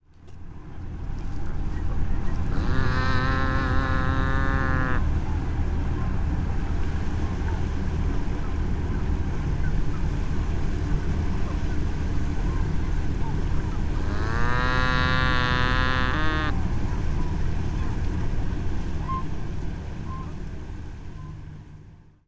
Nutria
Nutria are social animals that can often be heard calling to each other in mooing or pig-like grunts. Their vocalizations are generally used to indicate feeding times or as a way to attract mates.
nutria-call.mp3